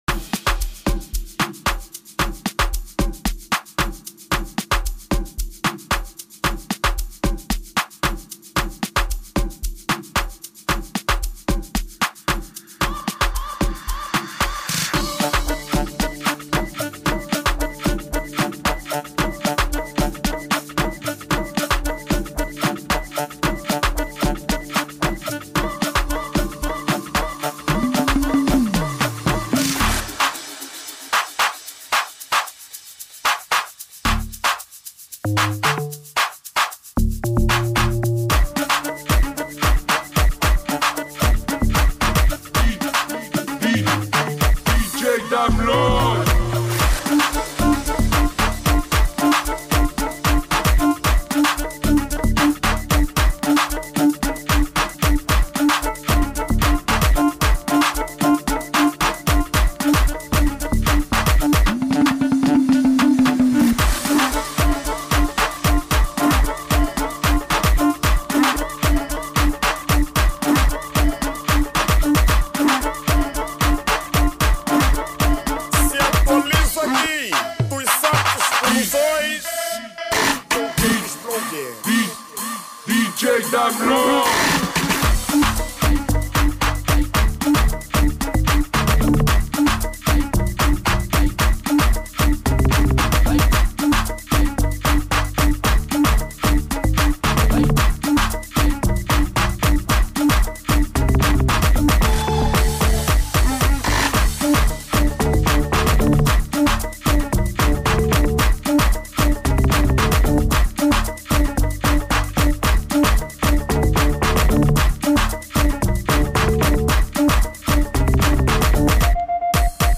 Categoria: Amapiano